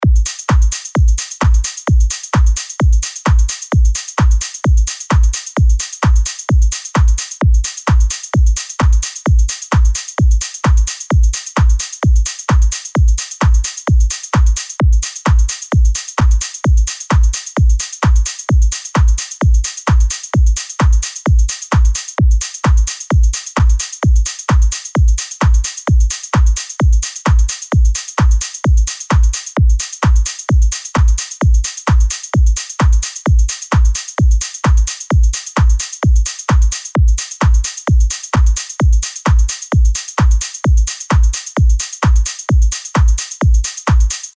LP 222 – DRUM LOOP – EDM – 130BPM